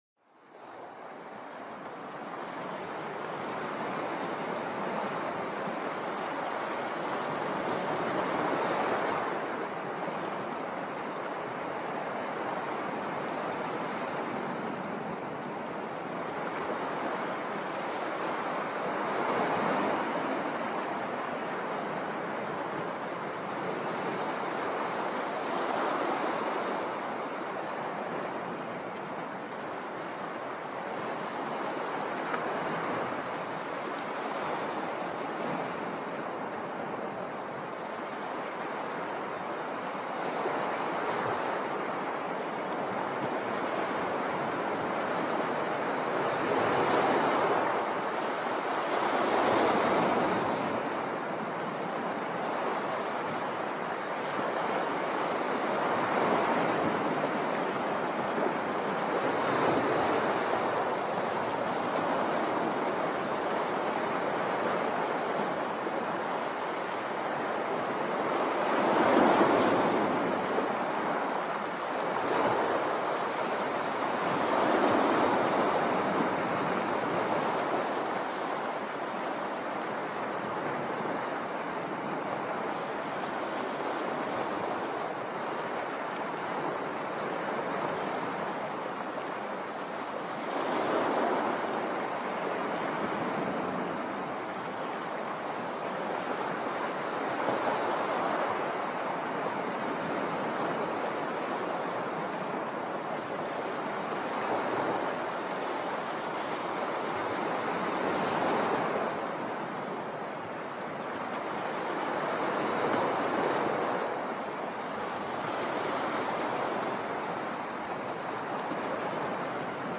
Doga-Sesleri-Deniz-Dalgasi-Dalga-Sesi-Rahatlatici-SanaLem.Com_.mp3